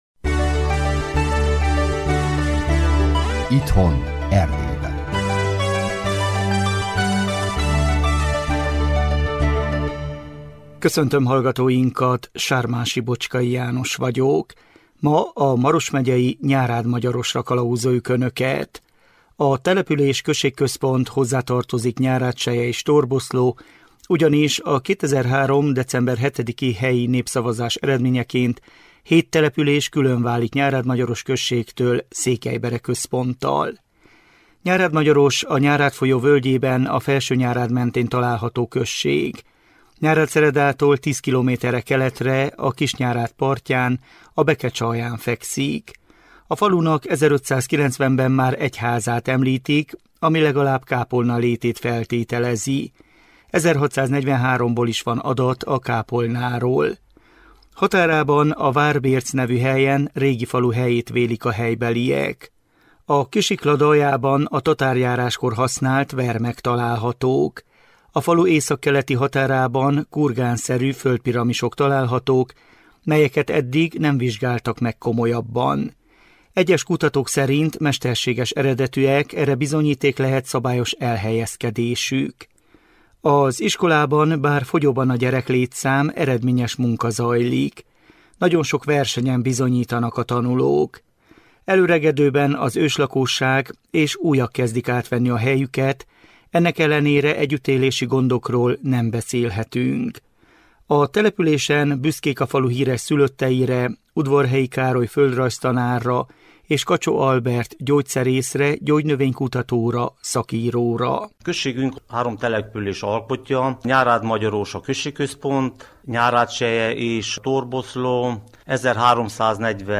Az április 6-i műsorban Kacsó Lajos polgármester